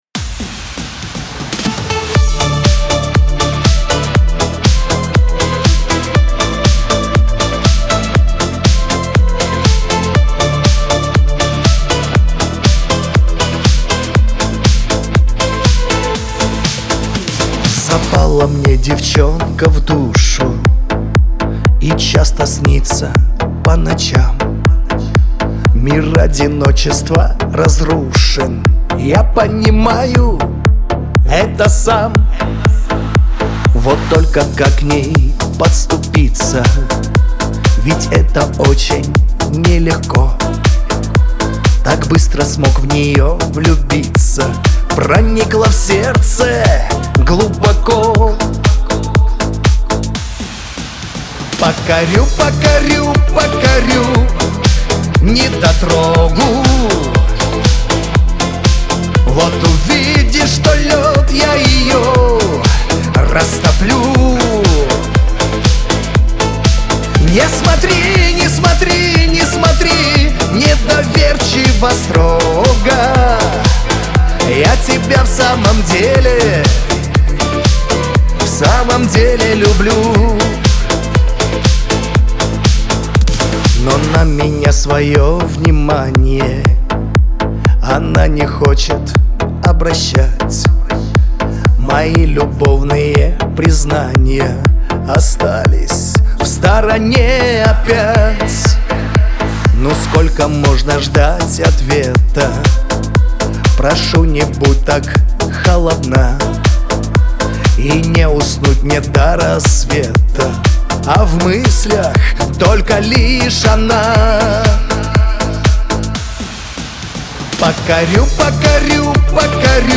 Лирика , грусть